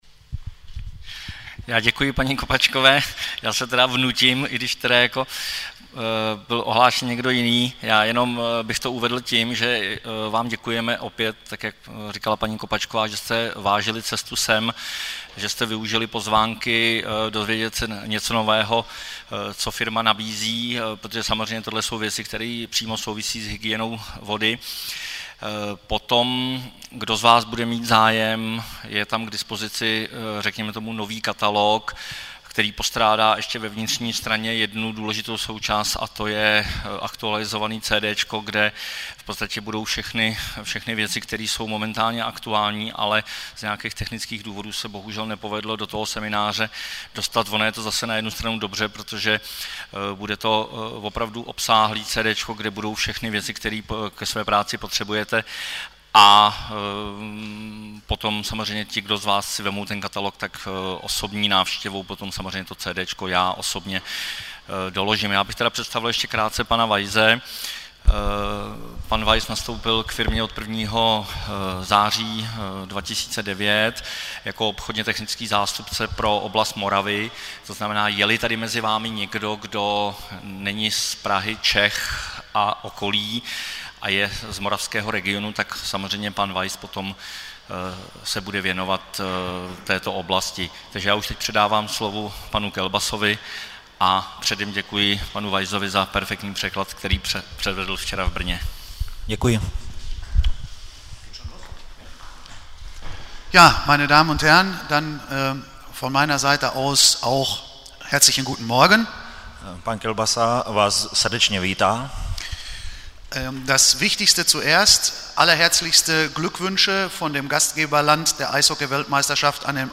Přednášky ze semináře Hygiena vody - aktuální povinnosti a připravované změny, který se konal v květnu 2010 a jehož generálním partnerem byla firma Kemper.